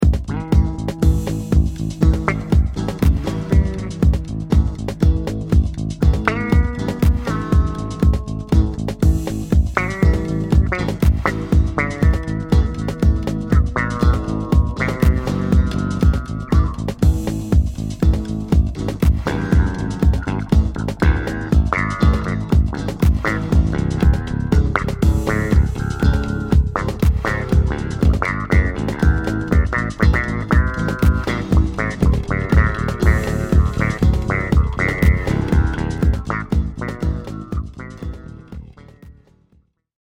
MOONPATROL - HIGH ENERGY ROCK'N'ROLL
Klampfe einstecken, Schlagzeug aussuchen und Aufnahme drücken.
Damit wäre die Stimmung der Nacht dann eingefangen und die Wache wurde zum 6-Stunden-Jam mit den Maschinen.